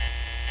LightFlickerLoop.wav